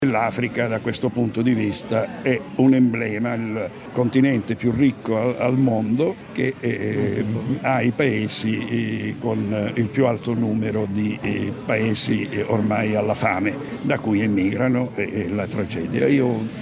GIORNO DELLA MEMORIA A PRAIA A MARE CON GLI STUDENTI DELL’ALTO TIRRENO